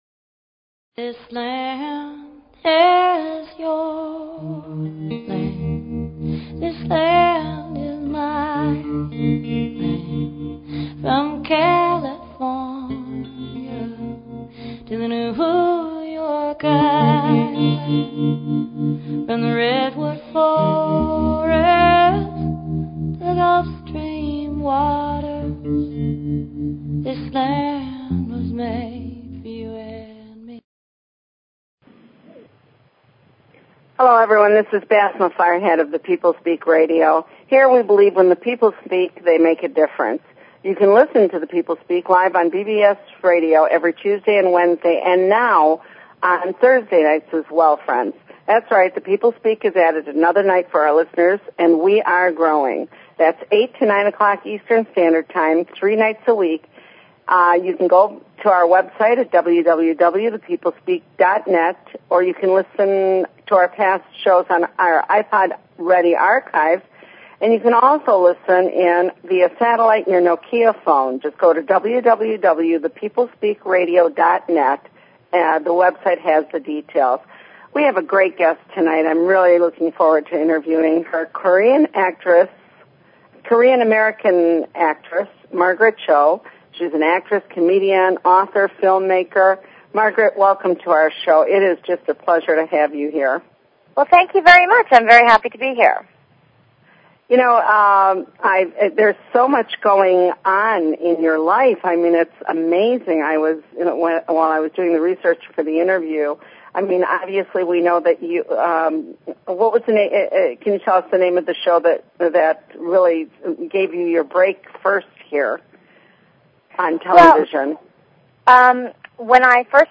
Guest, Margaret Cho